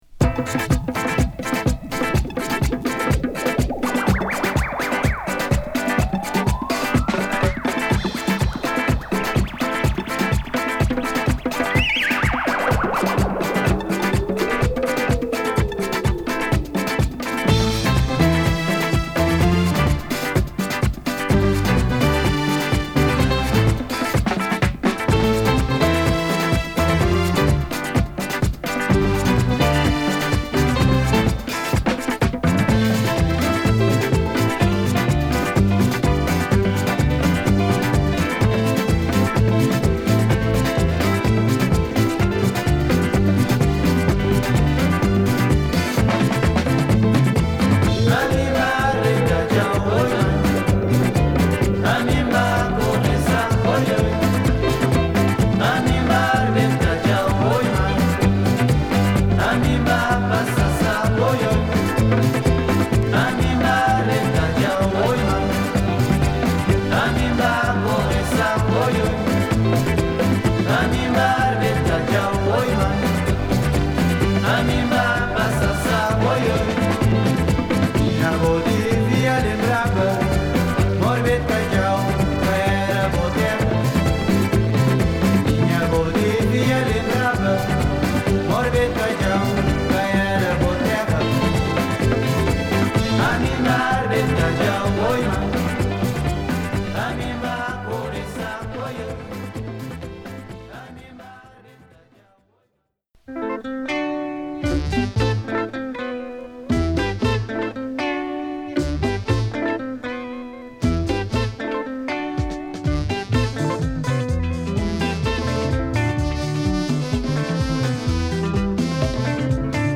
アフロブギーにエスニックなエッセンスを振りかけてポルトガル語のヴォーカルが乗る不思議な魅力が詰まった18曲！